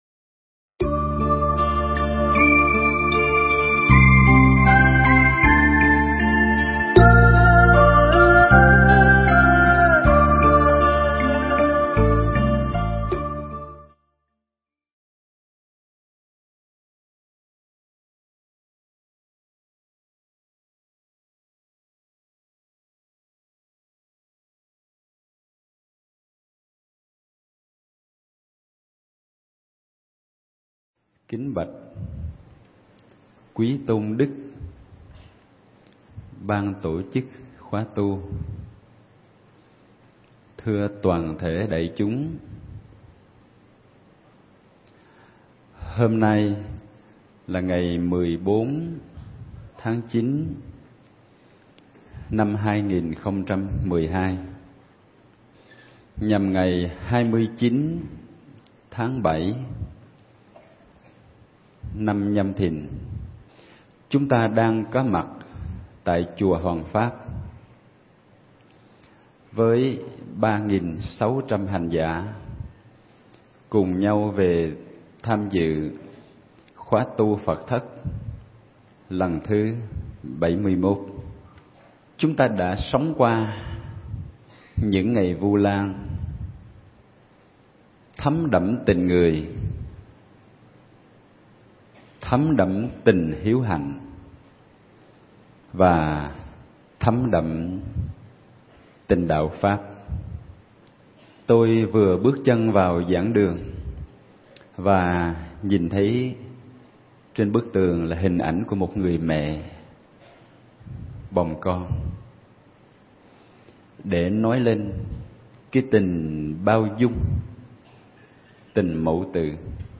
Nghe Mp3 thuyết pháp Vững Chãi Và Bao Dung